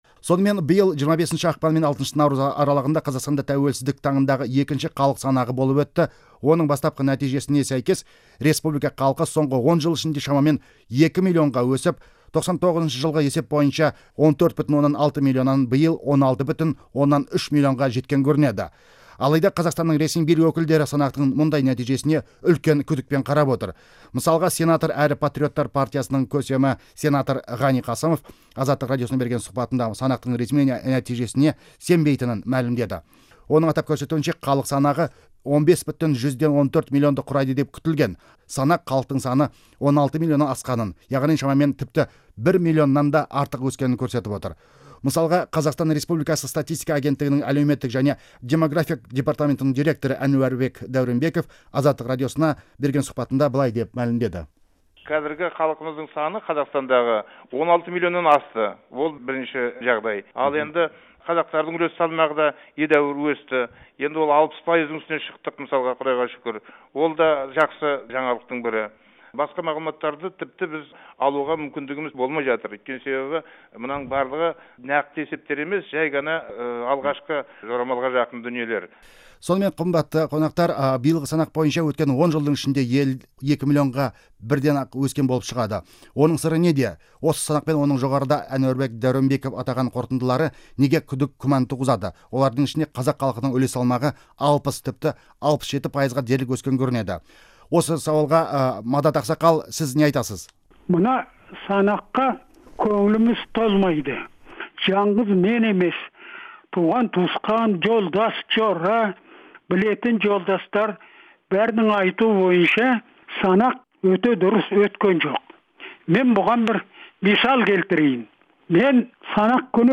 Халық санағына қатысты өткізілген дөңгелек үстел сұхбатын тыңдаңыз